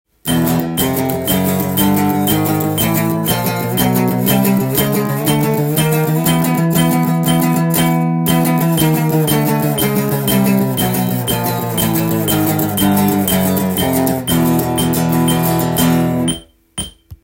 【パワーコードストローク練習】オリジナルTAB譜
３連符
パワーコードを０フレットから半音階で１２フレットまで上がっていく
TAB譜　メトロノームのテンポは１２０です。
右手がダウンダウンアップの順番になります。